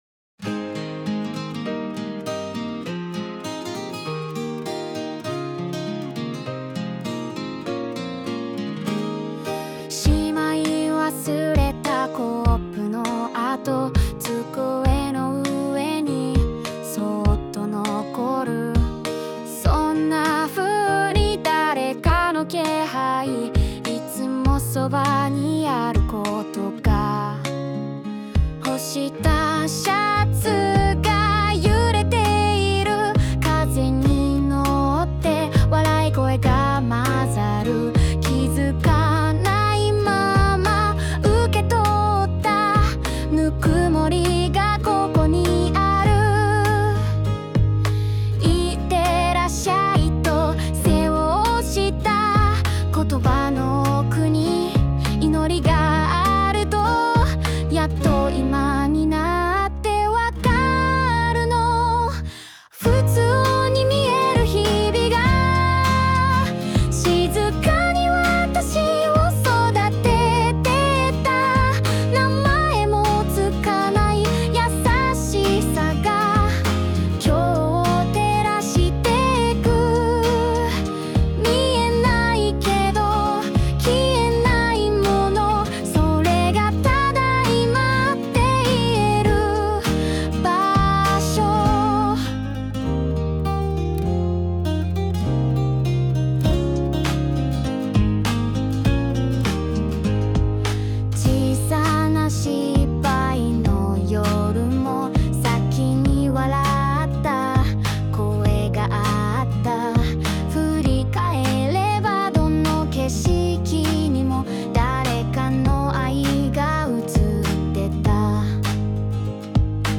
邦楽女性ボーカル著作権フリーBGM ボーカル
著作権フリーオリジナルBGMです。
女性ボーカル（邦楽・日本語）曲です。